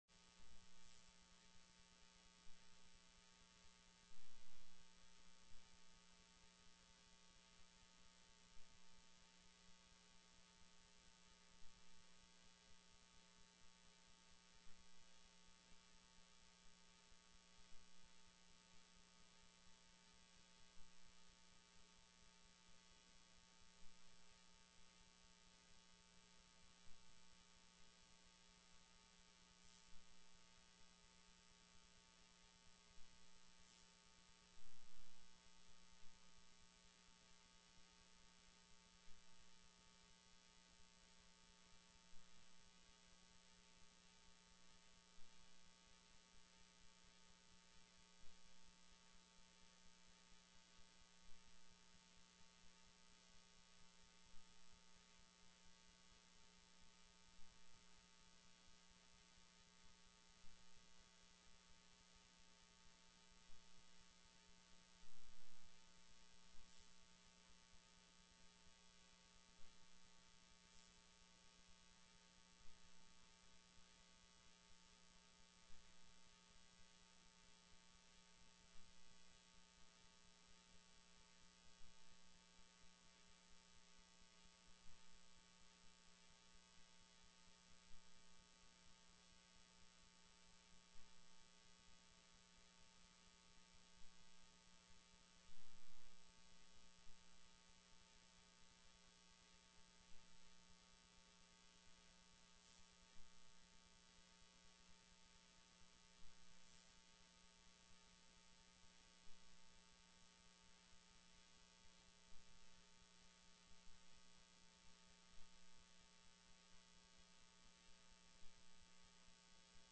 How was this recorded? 03/18/2009 01:30 PM House FINANCE